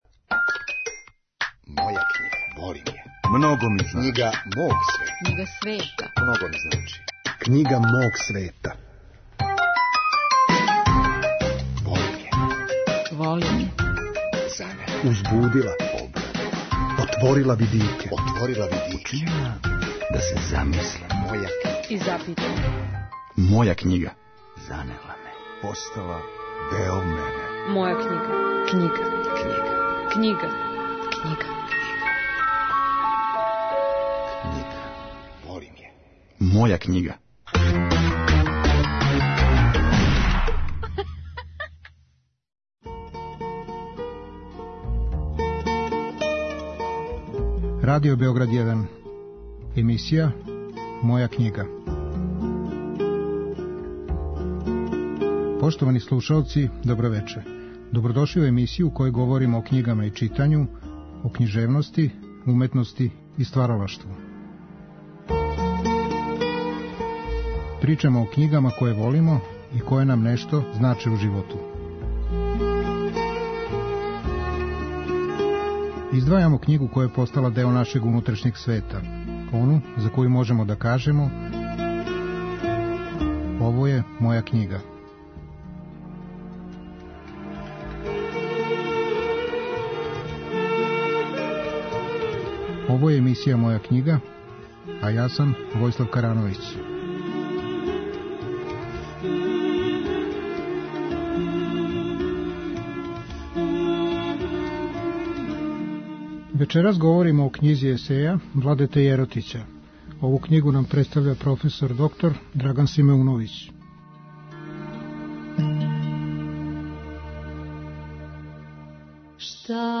Наш саговорник говори о томе шта му значе дела Владете Јеротића, и зашто сматра да је читалачки сусрет са тим делима прилика за истински духовни раст. Биће речи о делима, о раду и предавањима, о порукама и поукама Владете Јеротића.